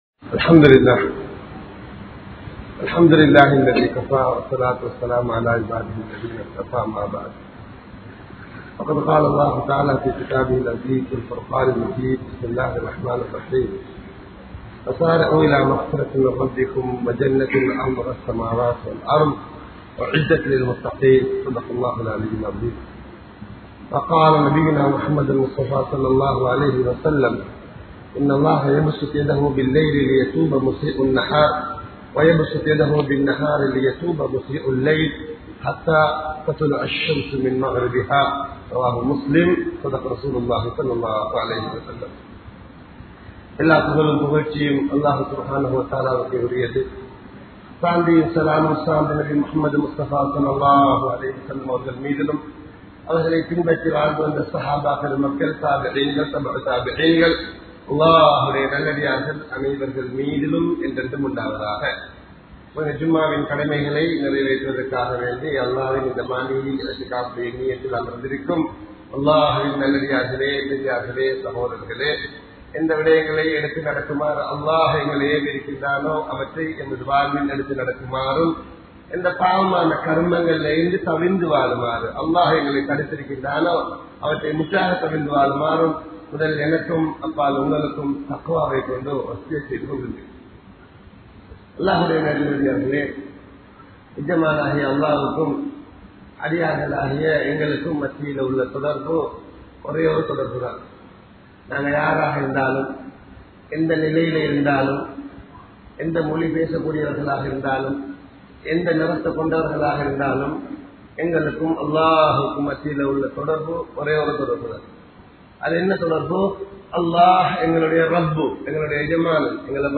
Allah`vukku Nantri Seluththum Adiyaan (அல்லாஹ்வுக்கு நன்றி செலுத்தும் அடியான்) | Audio Bayans | All Ceylon Muslim Youth Community | Addalaichenai